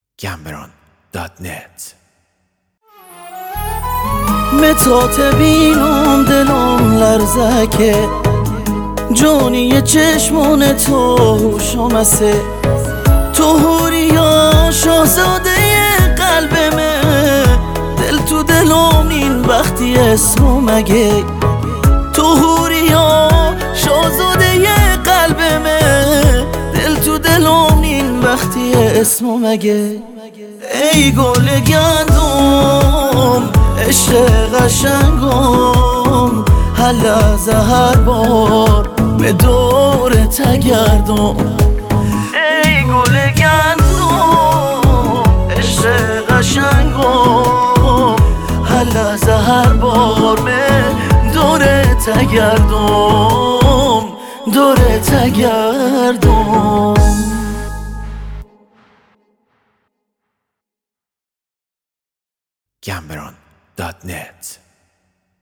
آهنگ بستکی